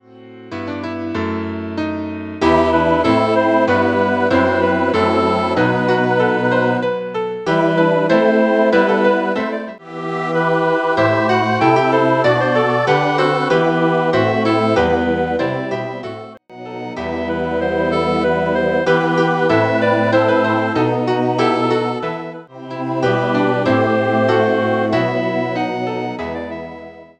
für Chor SATB / Oberstimme +Tasteninstrument